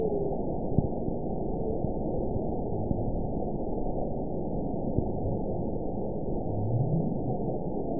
event 921985 date 12/24/24 time 10:04:34 GMT (4 months, 2 weeks ago) score 9.26 location TSS-AB04 detected by nrw target species NRW annotations +NRW Spectrogram: Frequency (kHz) vs. Time (s) audio not available .wav